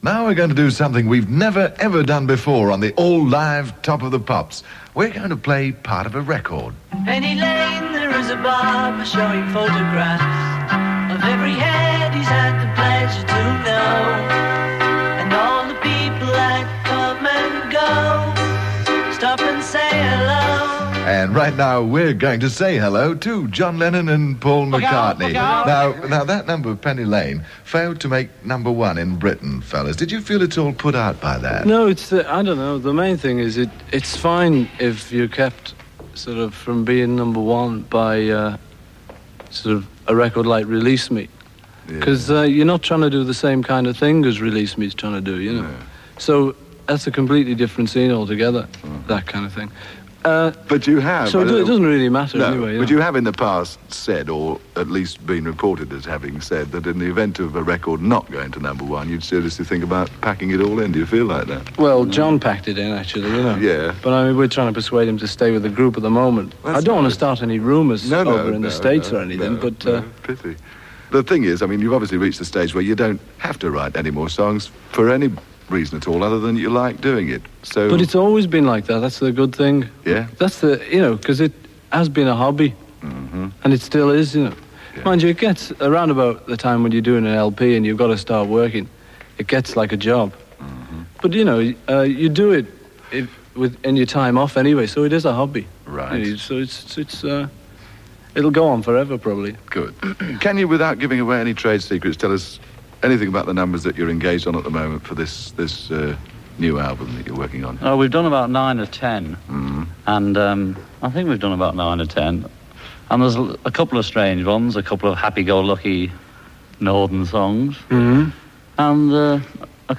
These interviews were recorded on reel E63372.
Studio Two 7.00pm-3.00am